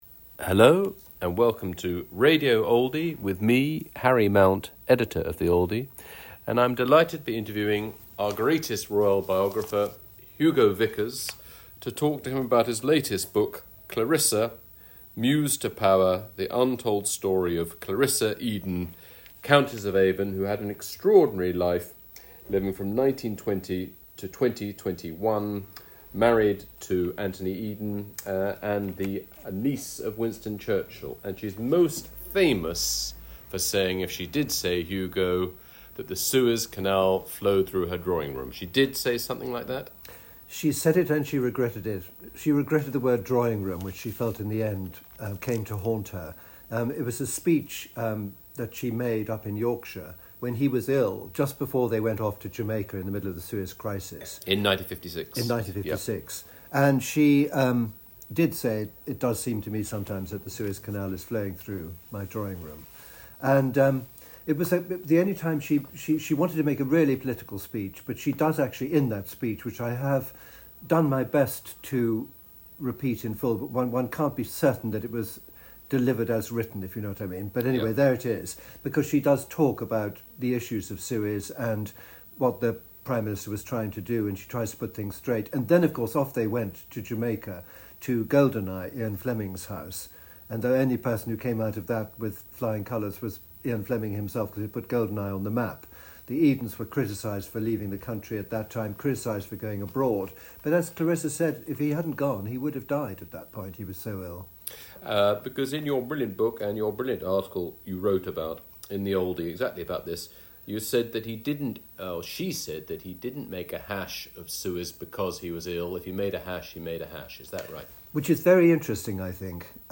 Oldie Podcast - Hugo Vickers in conversation with Harry Mount on Clarissa, Countess of Avon